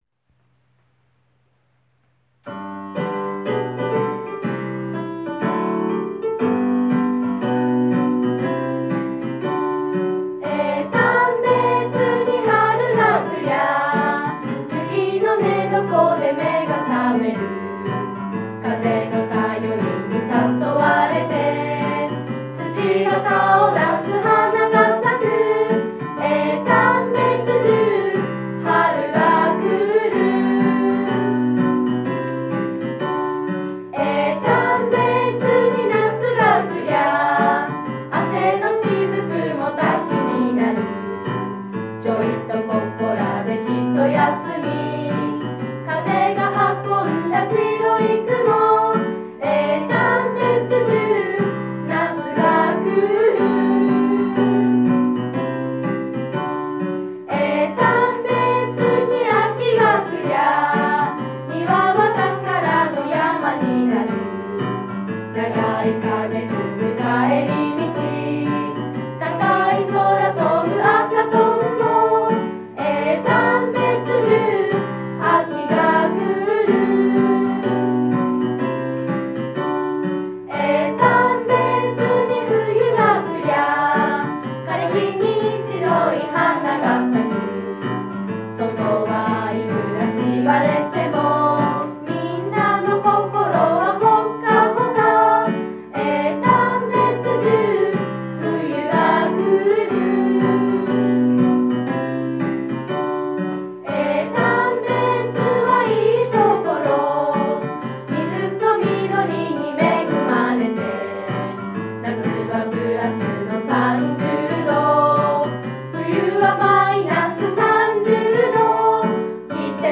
江丹別音頭.wav